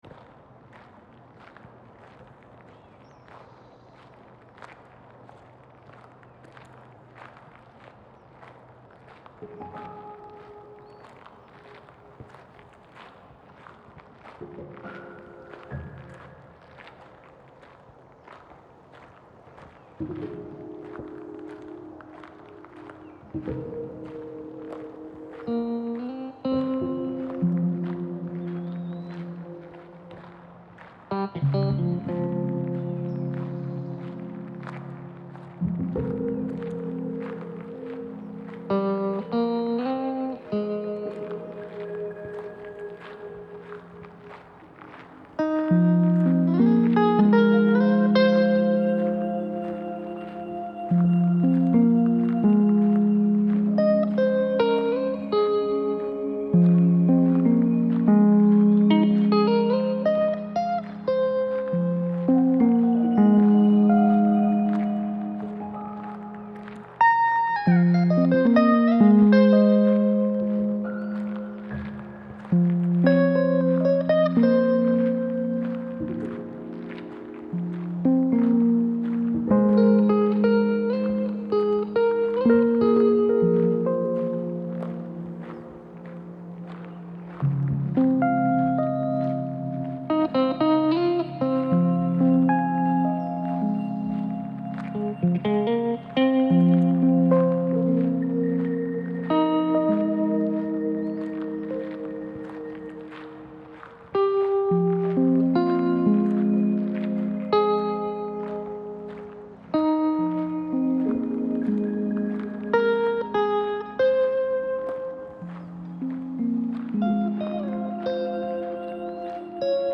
Pretty much following the same flow I would on a SP404 but with less resampling & FX …
used a couple LABS sounds, a field recording & guitar